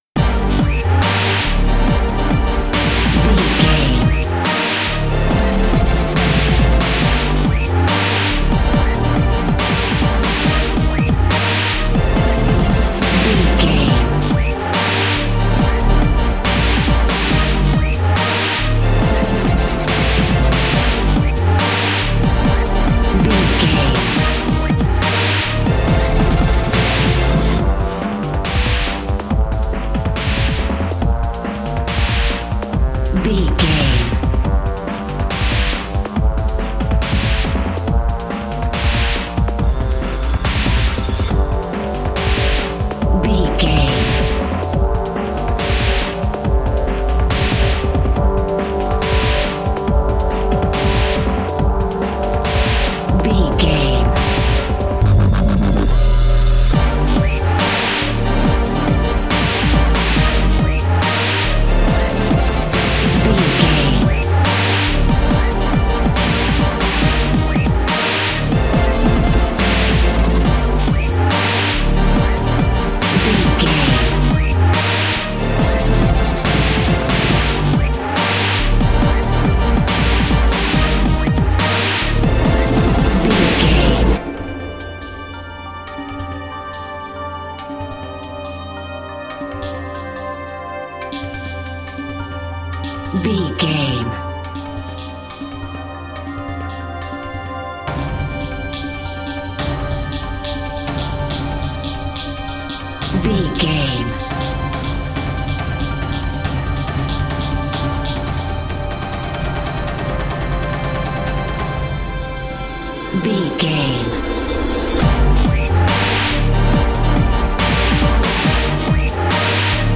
Epic / Action
In-crescendo
Ionian/Major
drum machine
synthesiser
driving drum beat